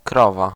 k k
krowa scam